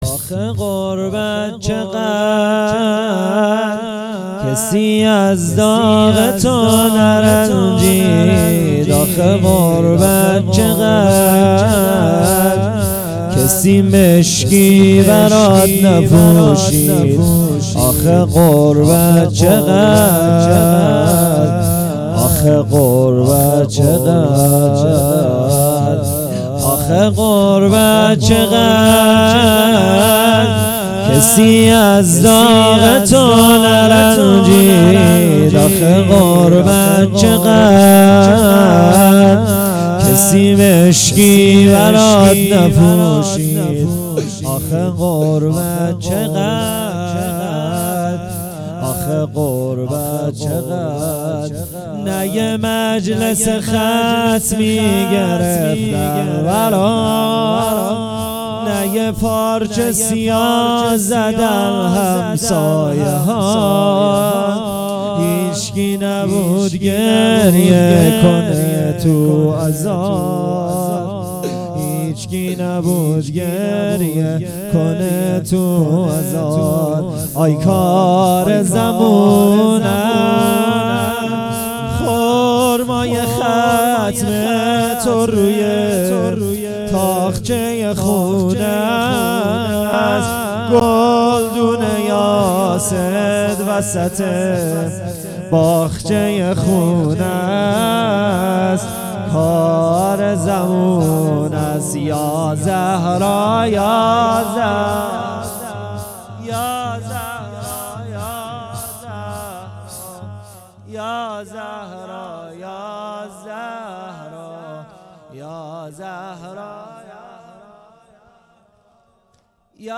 زمینه آخه غربت چقد